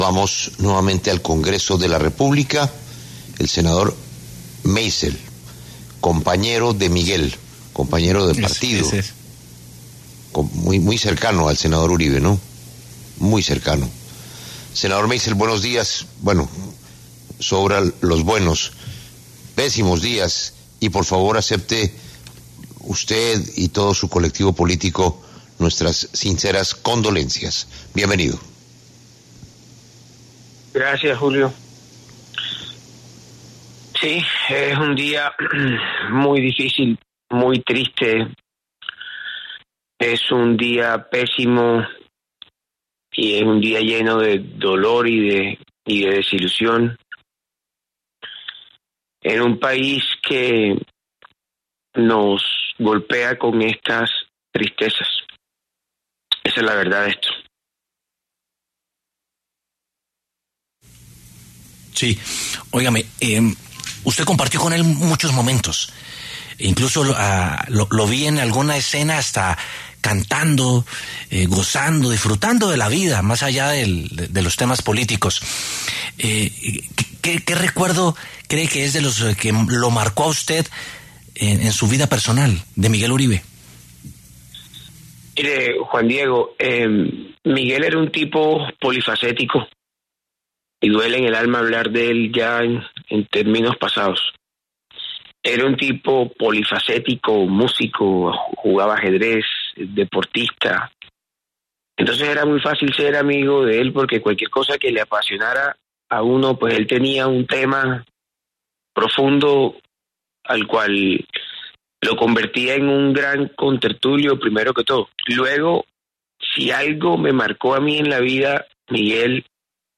En la mañana de este lunes, 11 de agosto, el senador del Centro Democrático, Carlos Meisel, habló en La W, con Julio Sánchez Cristo, sobre la muerte de Miguel Uribe, también senador de esa colectividad y, además, precandidato presidencial.